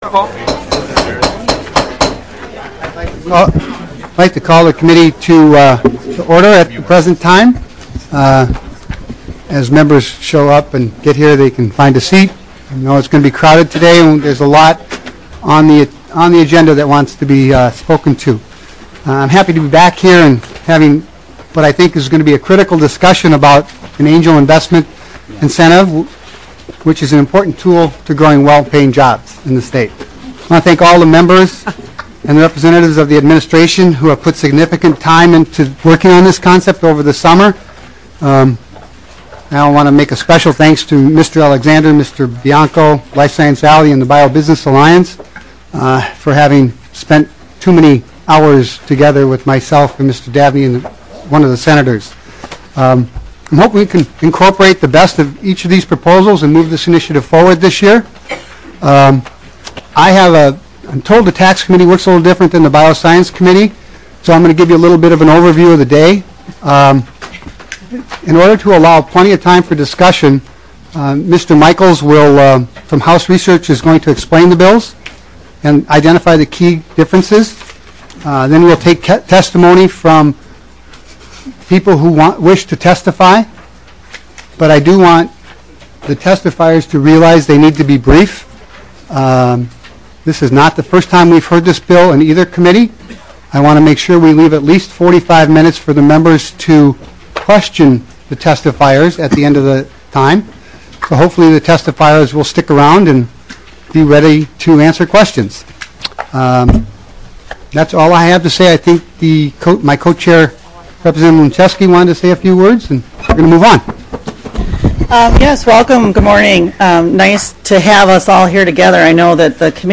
Taxes FORTY SEVENTH MEETING - JOINT MEETING - Minnesota House of Representatives
05:15 - Gavel. 10:05 - House Research overview of "Angel Investor" bills. 23:30 - Testimony on "Angel Investor" bills".